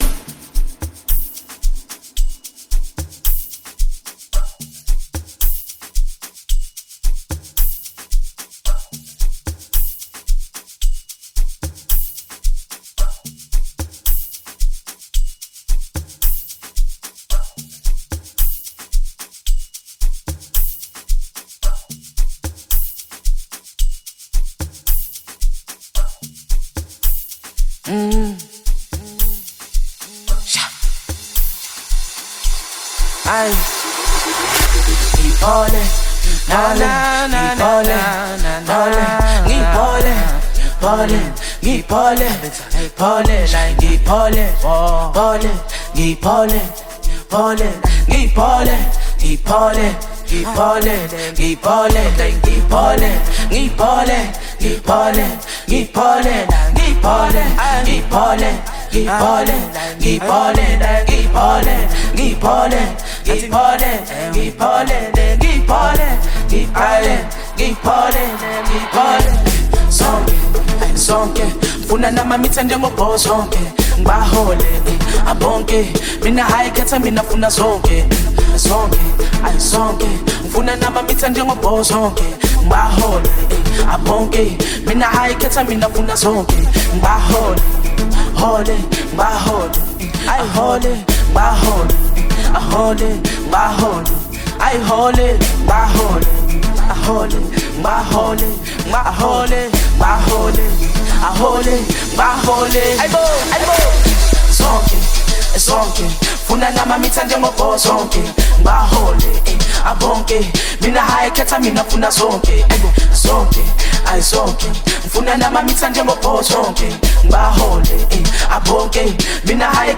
Amapiano-infused masterpiece